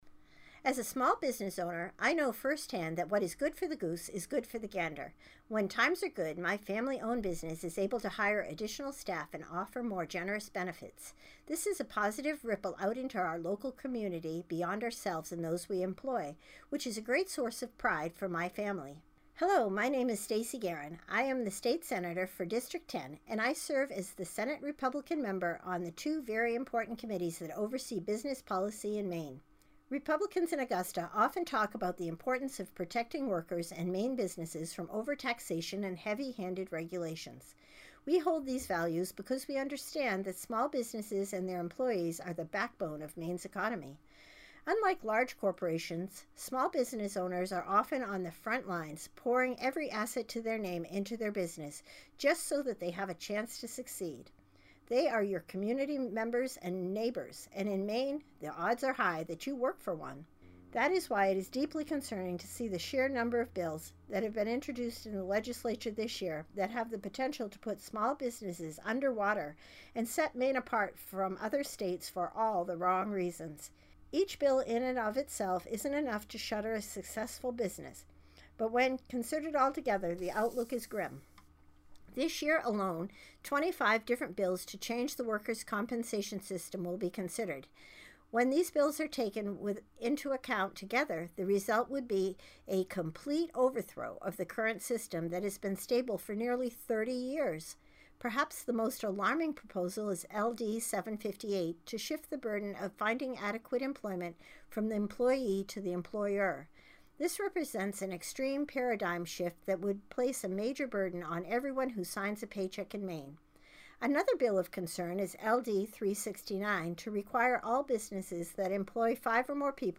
March 15 Radio Address